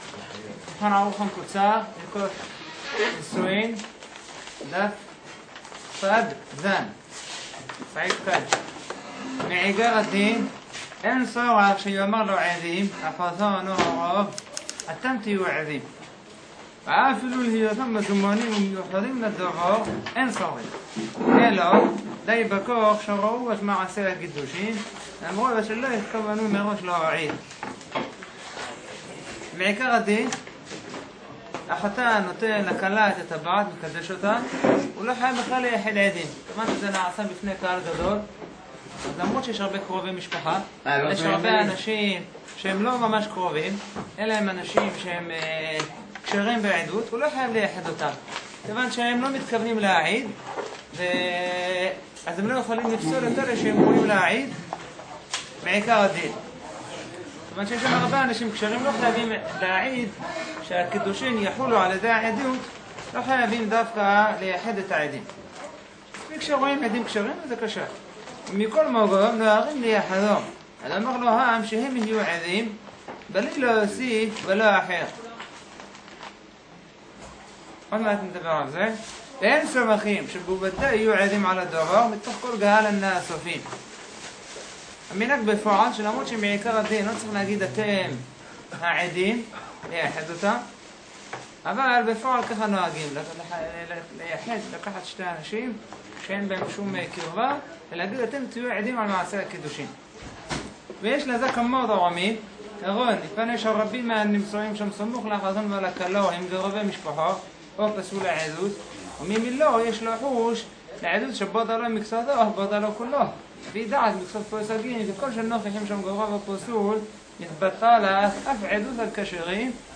נמסר במסגרת השיעור המקדים לשיעורו השבועי